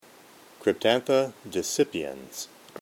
Pronunciation/Pronunciación:
Cryp-tán-tha  de-cí-pi-ens